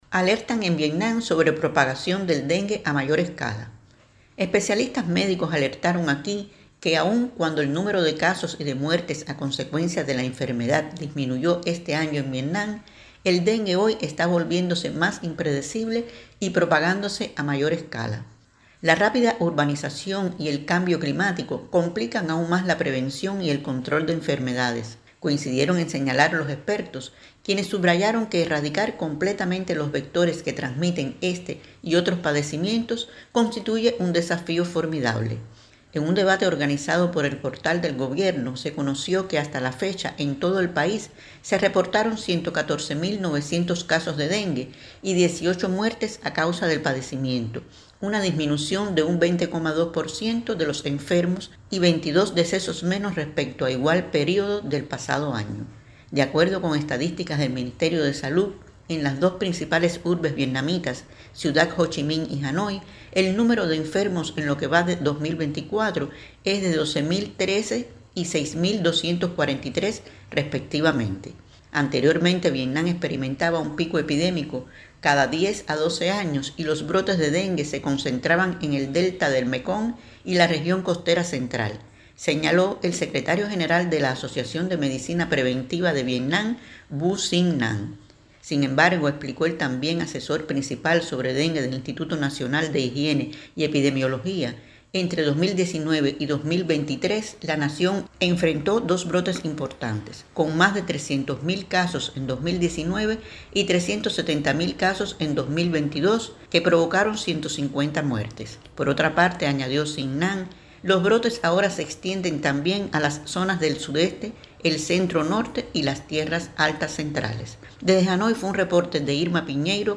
desde Hanoi